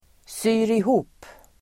Uttal: [sy:rih'o:p]